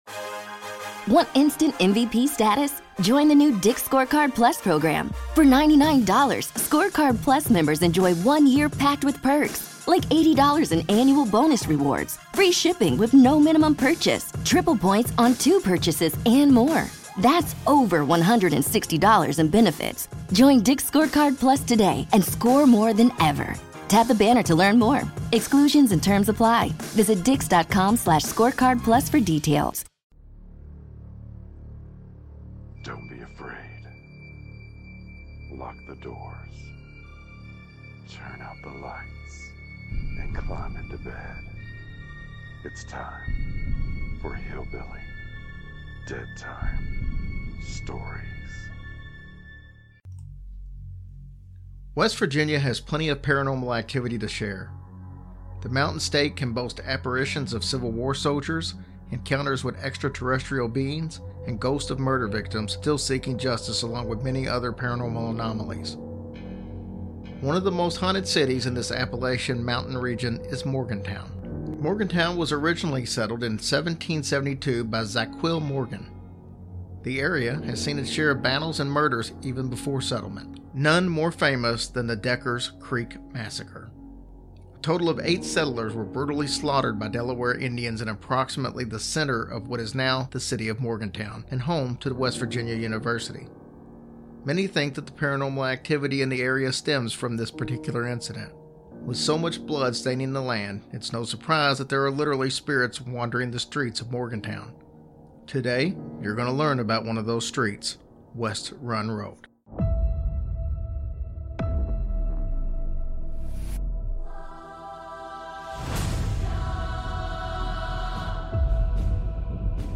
Introduction voice over